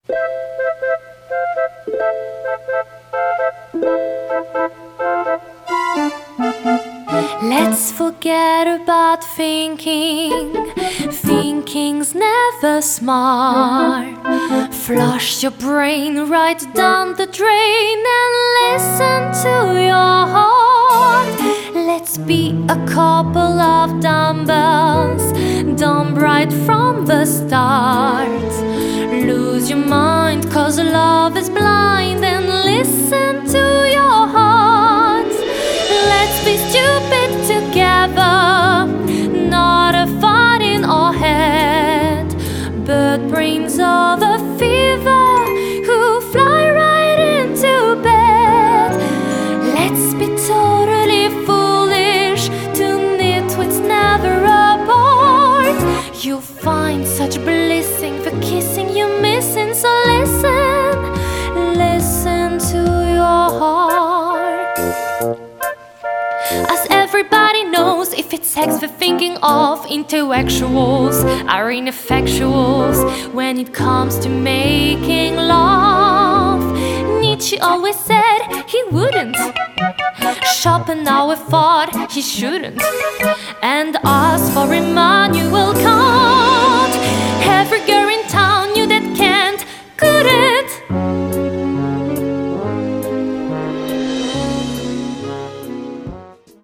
Chanteuse
- Soprano